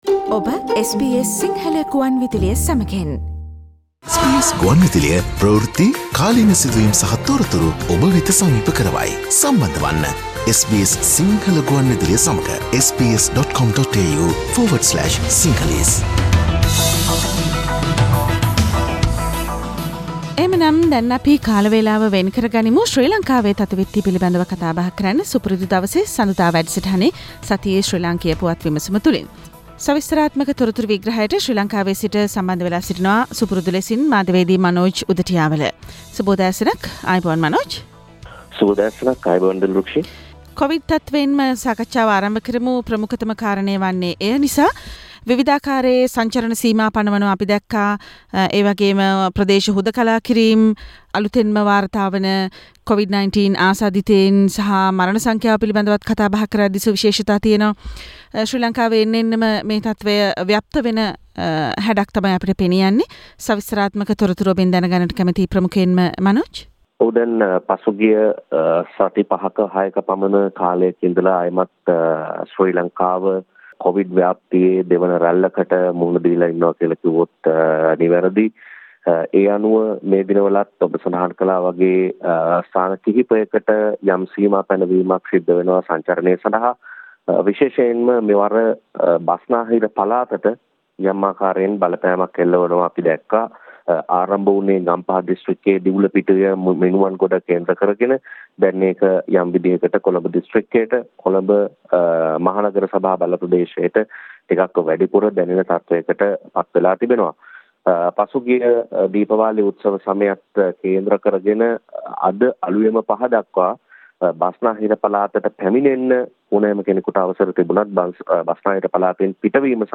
Sri Lankan news wrap Source: SBS Sinhala